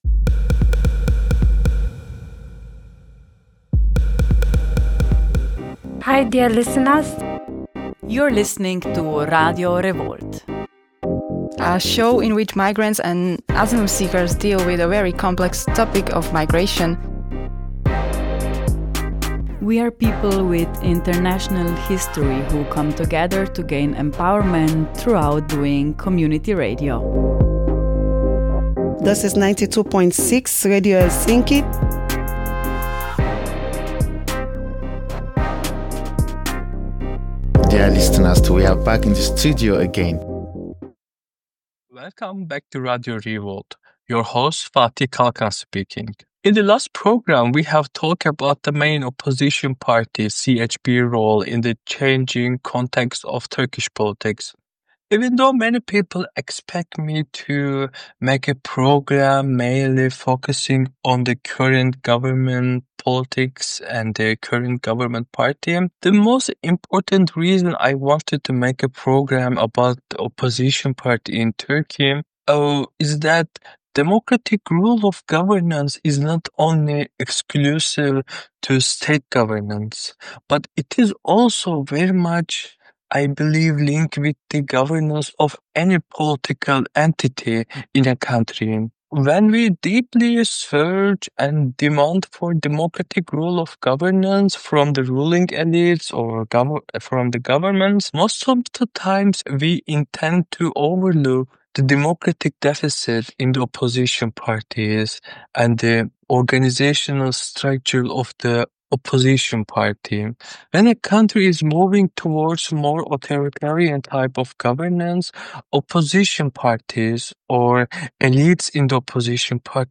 The conversation examines how geography, history, and national identity shape regional rivalries: why the Black Sea remains relatively stable while the Mediterranean faces perpetual tension; how schoolbooks, media, and literature reinforce national myths; and why every decade brings a new crisis without resolving the old ones.